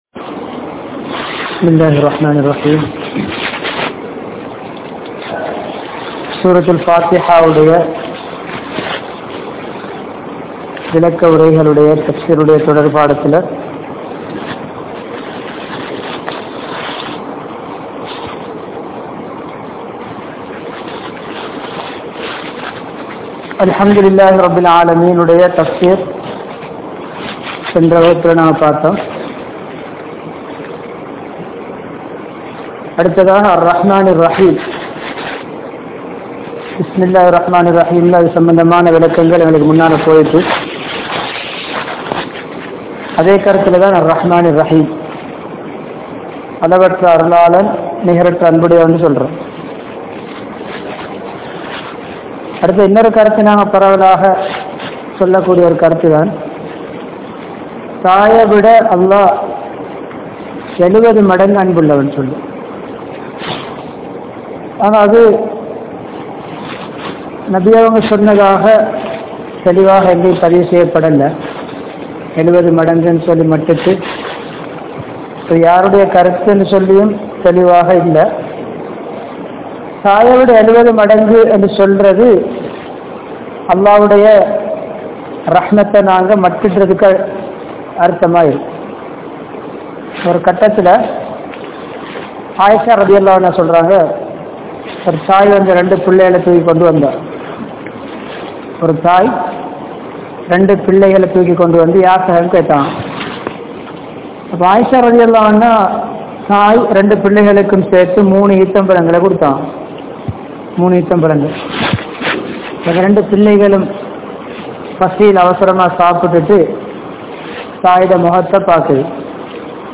Sura Fathiha (Thafseer 03) | Audio Bayans | All Ceylon Muslim Youth Community | Addalaichenai